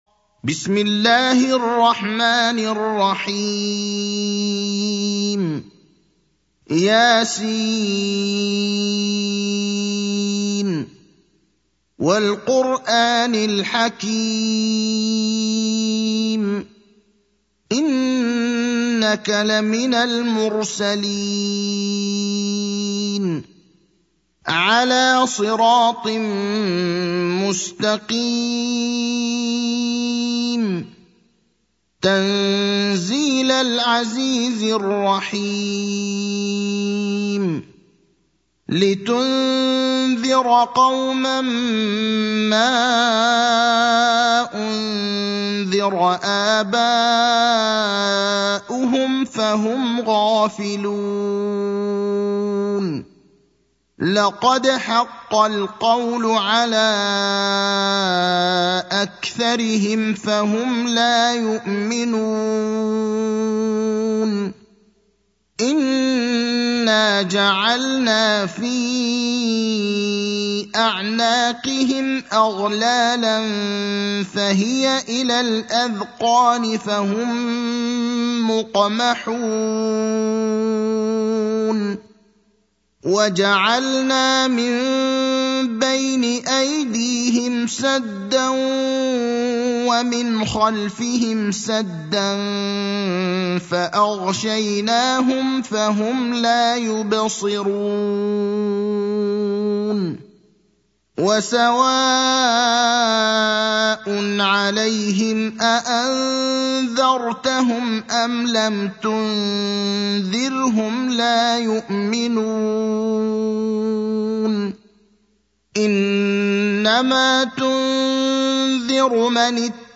المكان: المسجد النبوي الشيخ: فضيلة الشيخ إبراهيم الأخضر فضيلة الشيخ إبراهيم الأخضر يس (36)